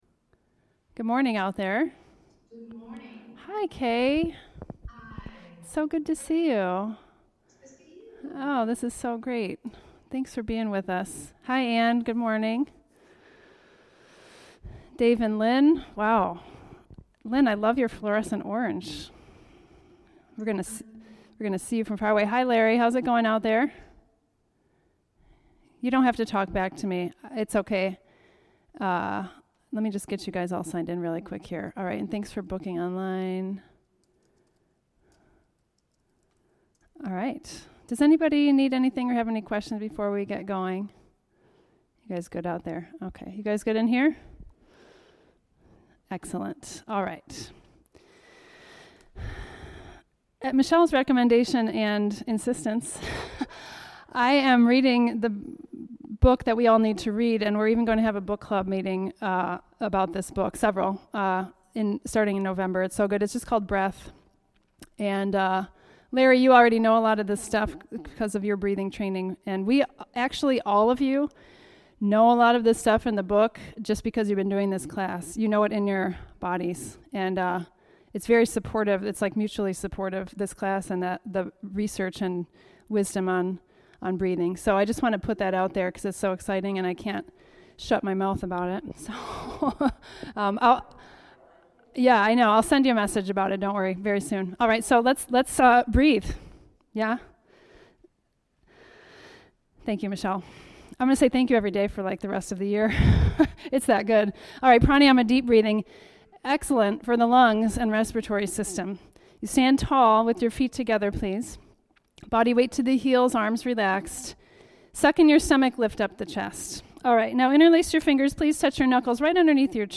Thursday, October 15, 2020 9:00 am class